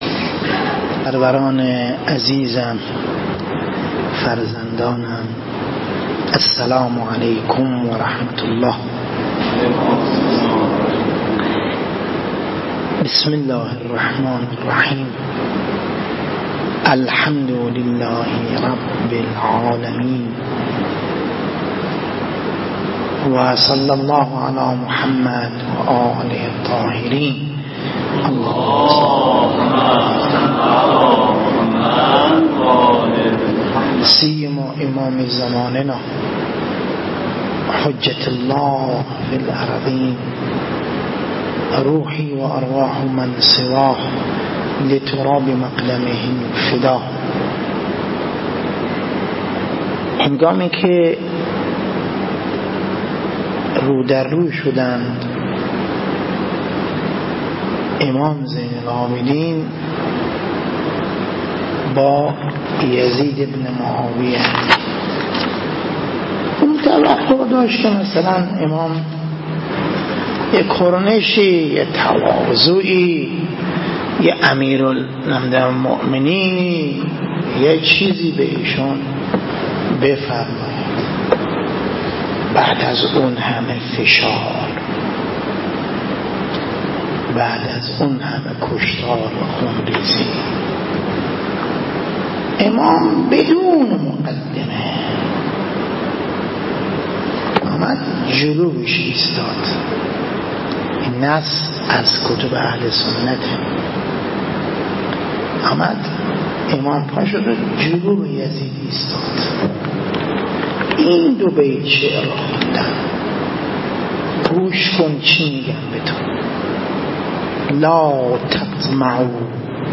مراسمات
افتتاحیه دوره تابستانه مدرسه عالی امام حسین علیه السلام 1402
همراه با برنامه روضه و توسل به ساحت نورانی امام حسین علیه السلام